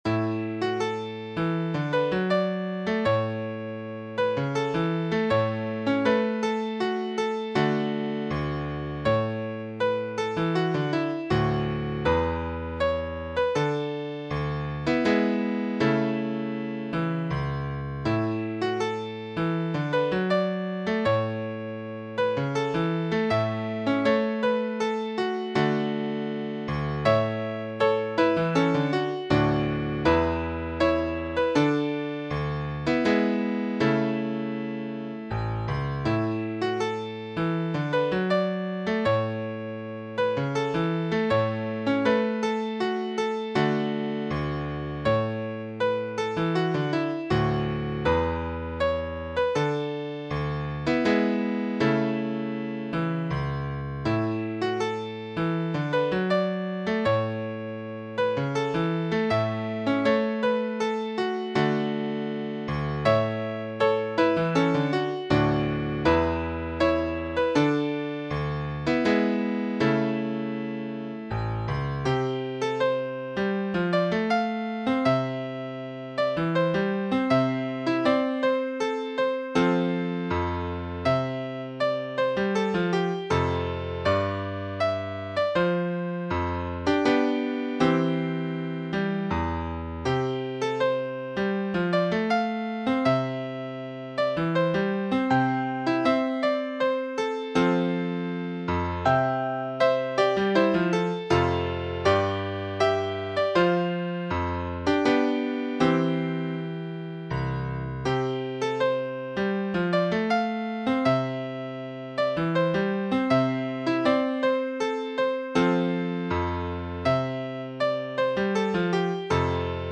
This pentatonic melody (but for one note) is an instance of "iorram" (rowing song).